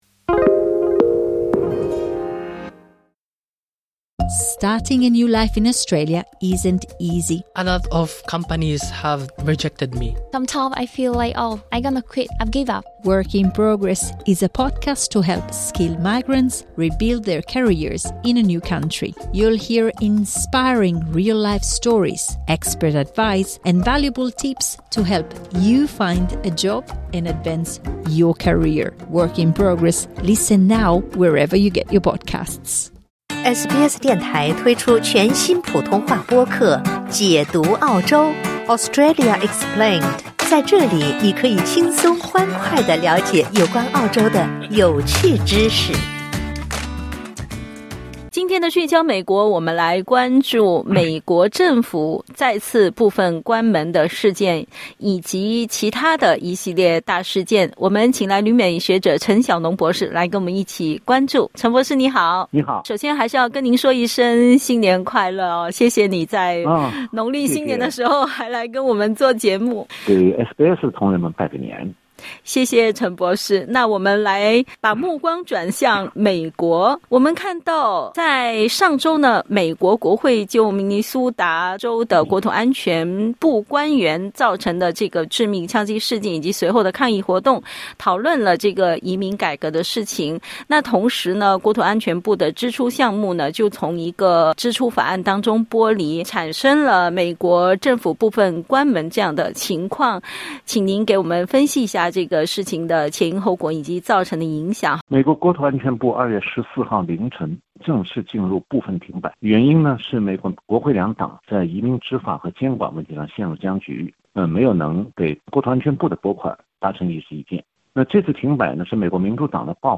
今天的聚焦美国我们关注：国土安全部陷入停摆、特朗普撤销温室气体有害公共健康裁定、大陪审团拒绝起诉六名民主党议员煽动叛乱。 点击音频收听详细采访